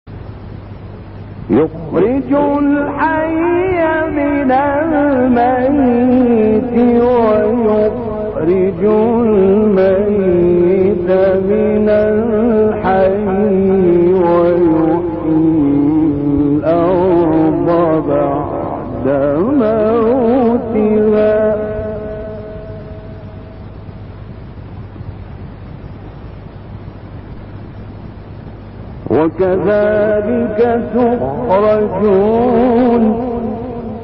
রাগিব মুস্তাফা গালুশের সুললিত কণ্ঠে সূরা রূমের তিলাওয়াত
এক মাহফিলে মিশরের বিখ্যাত ক্বারি রাগিব মুস্তাফা গালুশ এই সূরাটি তিলাওয়াত করেছেন।
ক্বারি রাগিব মুস্তাফা গালুশ এই তিলাওয়াতটি ৮০ দশকে মিশরে অনুষ্ঠিত এক মাহফিলে তিলাওয়াত করেছেন। এই মাহফিলে তিনি সূরা রূমের ১ থেকে ৩০ নম্বর আয়াত পর্যন্ত তিলাওয়াত করেছেন।